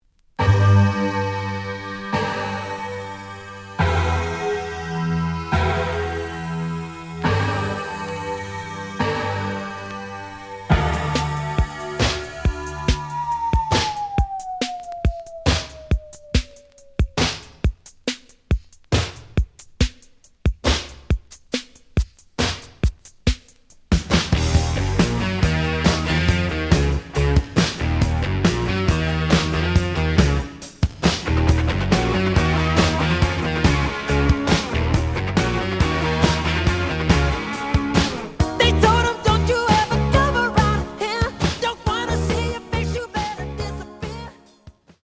試聴は実際のレコードから録音しています。
The audio sample is recorded from the actual item.
●Format: 7 inch
●Genre: Disco